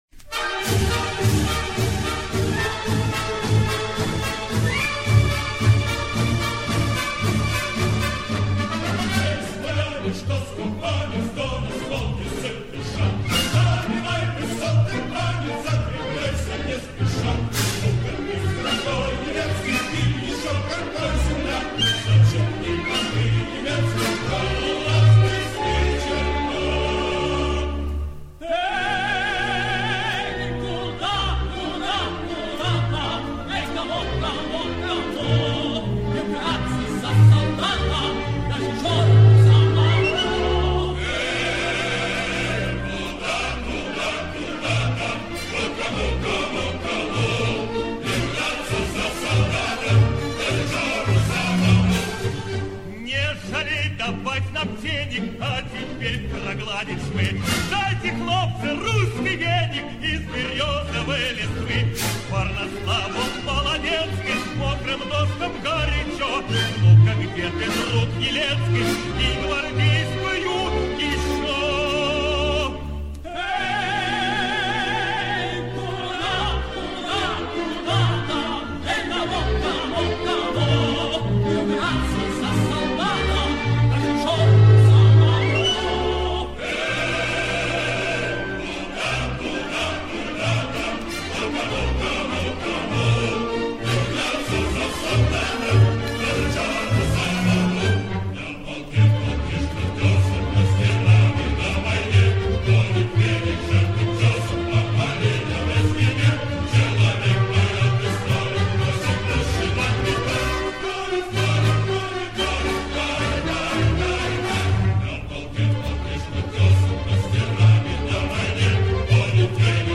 С пластинок к 30-летию Победы.